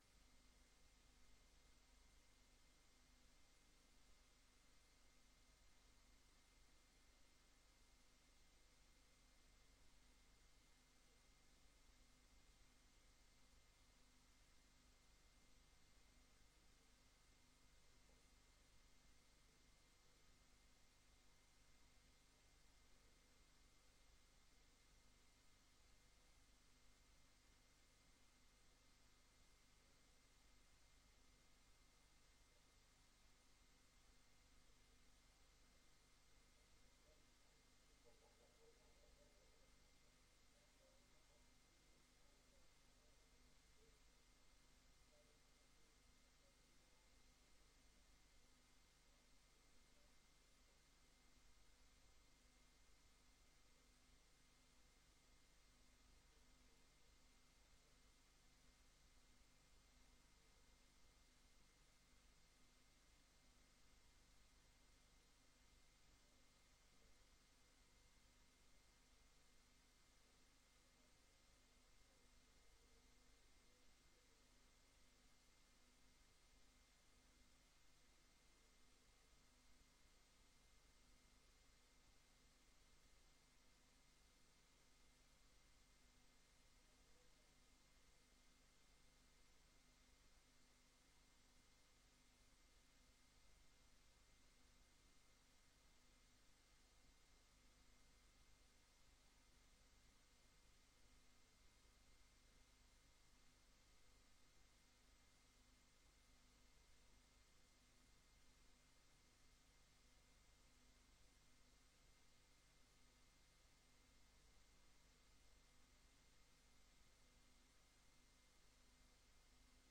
Raadsvergadering Papendrecht 25 september 2025 20:00:00, Gemeente Papendrecht
Locatie: Raadzaal